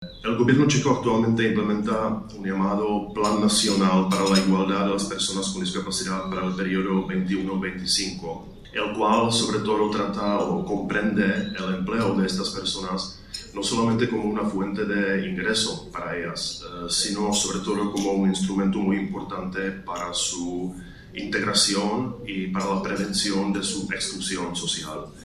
Embajadores, cónsules y consejeros de embajadas participaron en la Embajada de Francia en España en el VI Encuentro Diplomacia para la Inclusión organizado por el Grupo Social ONCE y la Academia de la Diplomacia, bajo el patrocinio del embajador francés en nuestro país, que ostenta la presidencia semestral del Consejo de la UE.